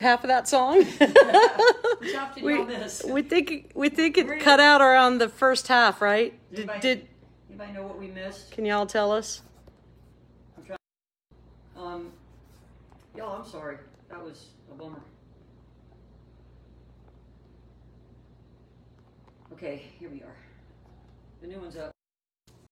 (captured from the facebook livestream)
06. talking with the crowd (0:22)